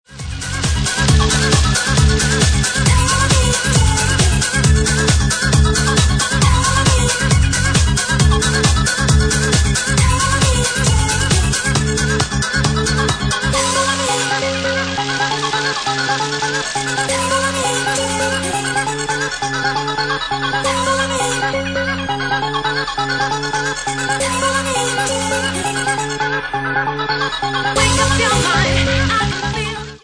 Bassline House at 136 bpm